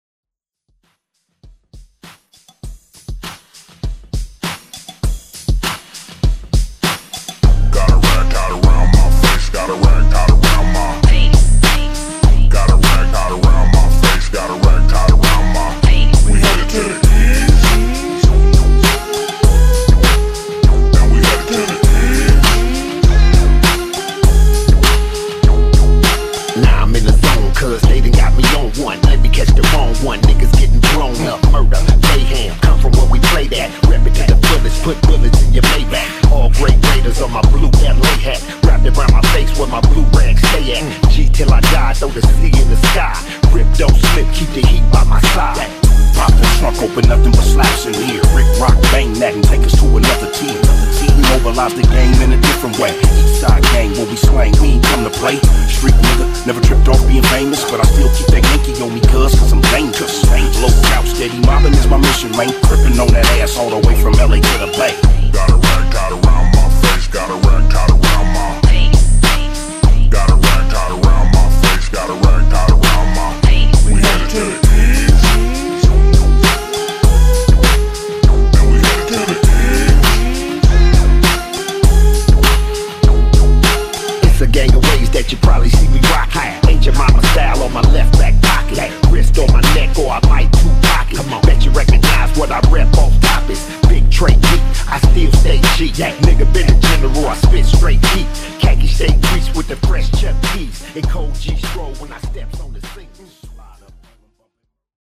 Genre: 90's
Clean BPM: 126 Time